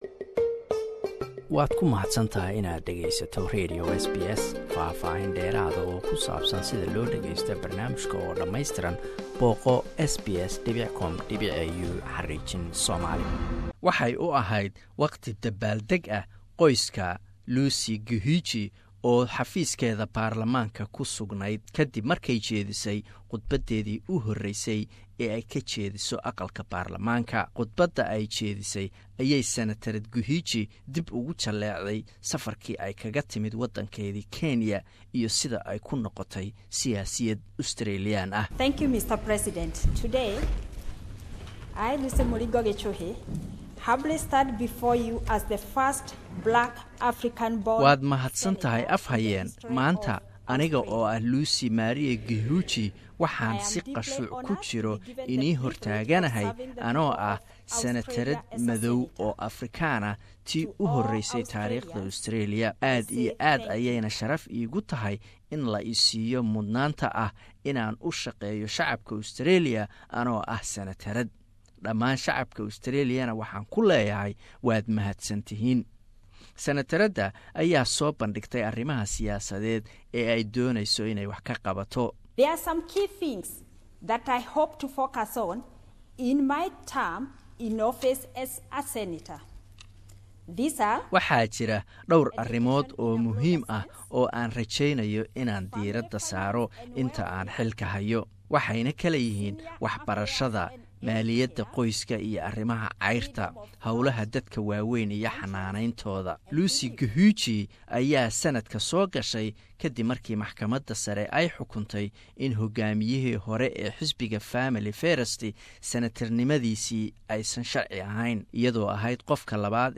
Lucy Gichuhi oo ah Senatorad madaxbanaan ayaa qudbadeedii u horaysay ka jeedisay baarlamaanka. Qudbadaas ayay ku sheegtay inay u dagalamidoonto waxbarashada iyo dad da'da ah.